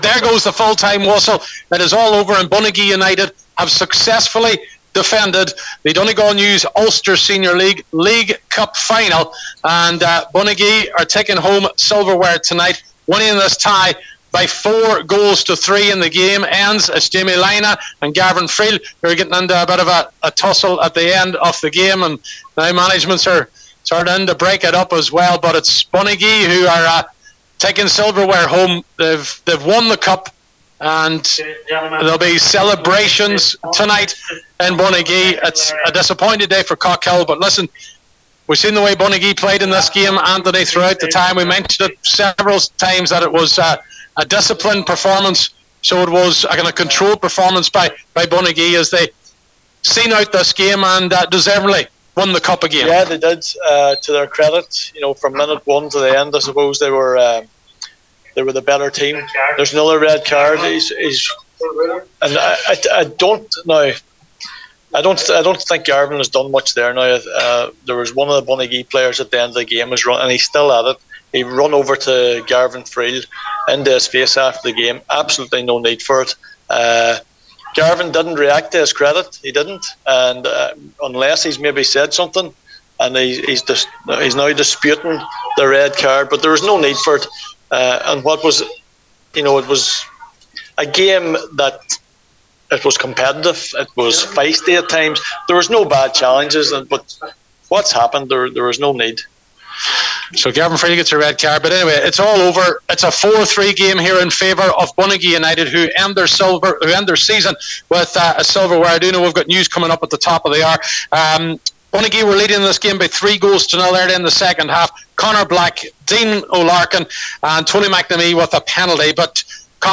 full time report